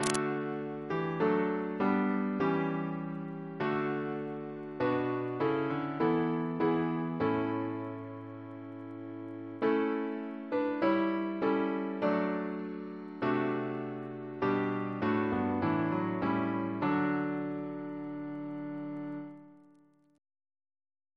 Double chant in D Composer: Sir John Goss (1800-1880), Composer to the Chapel Royal, Organist of St. Paul's Cathedral Reference psalters: ACB: 64; ACP: 197; PP/SNCB: 63